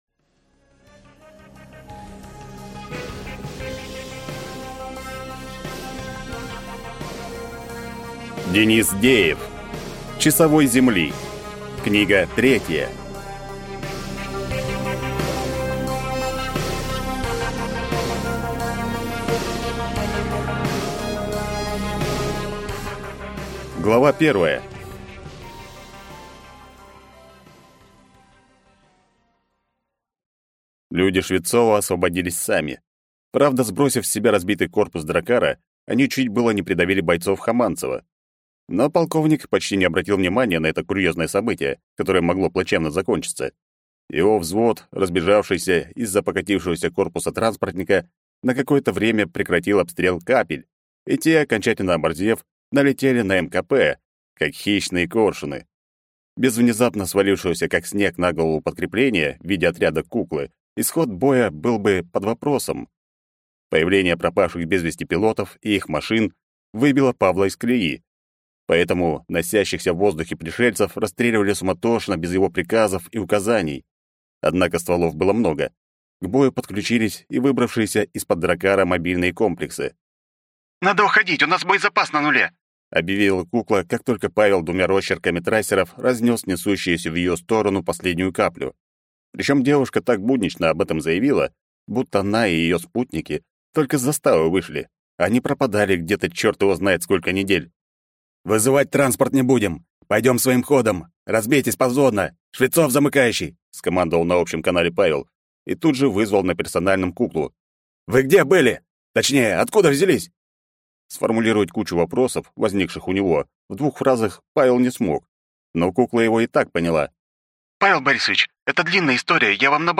Аудиокнига Часовой Земли. Книга 3 | Библиотека аудиокниг